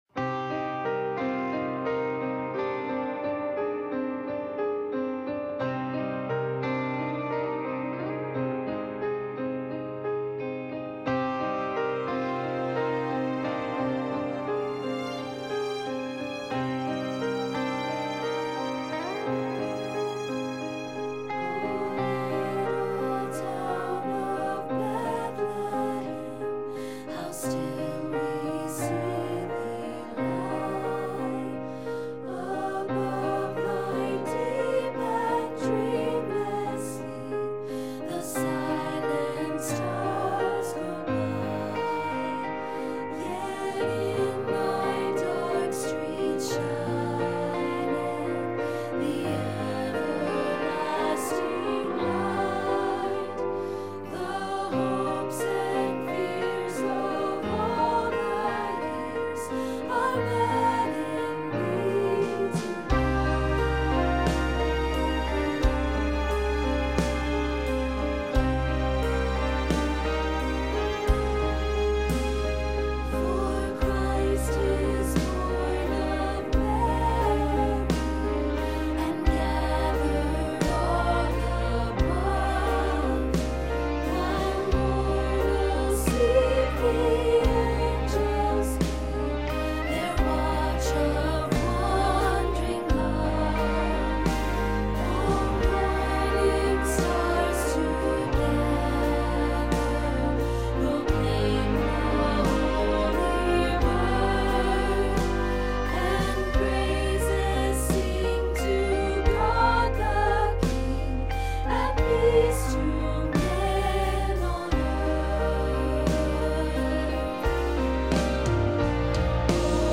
There are full tracks to listen to, and individual tracks for each voice part.
O Little Town-Bass
05-O-Little-Town-Bass-2.wma